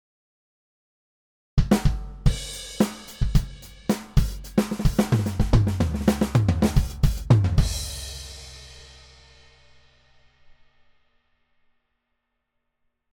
VQ Drums_Before
VQ-Drums_Before.mp3